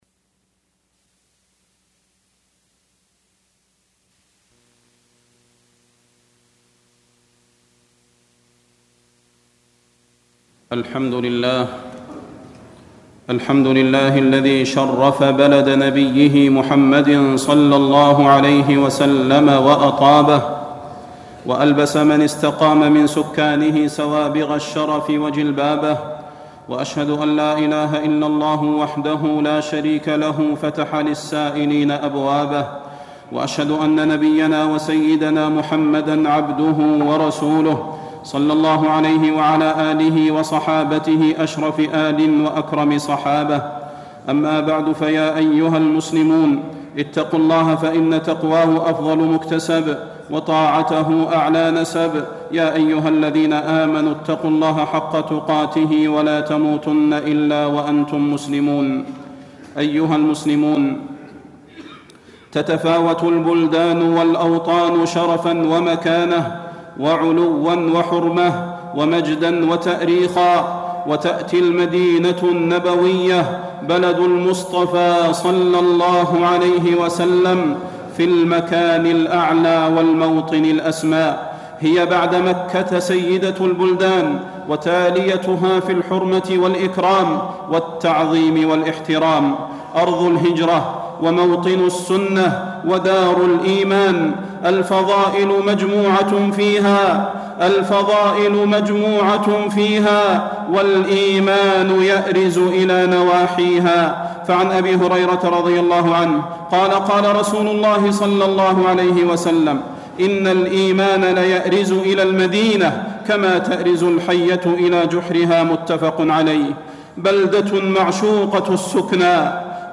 تاريخ النشر ١٧ جمادى الآخرة ١٤٢٤ هـ المكان: المسجد النبوي الشيخ: فضيلة الشيخ د. صلاح بن محمد البدير فضيلة الشيخ د. صلاح بن محمد البدير فضائل المدينة